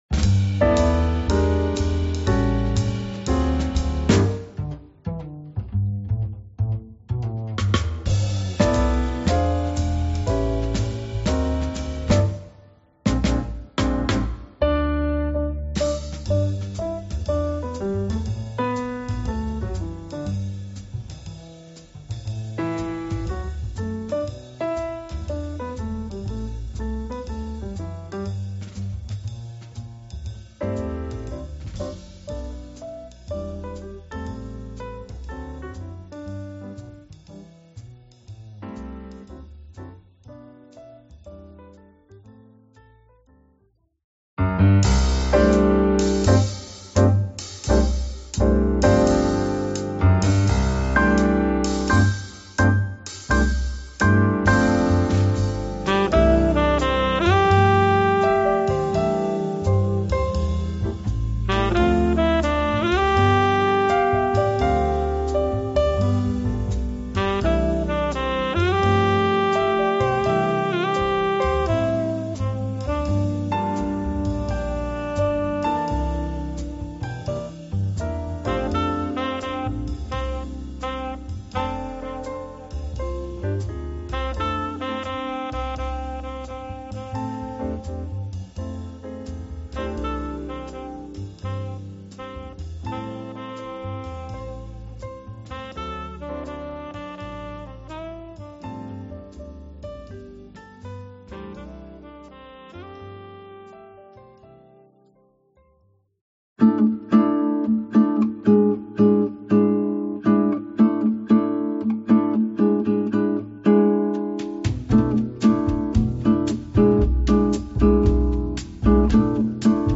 classic holiday songs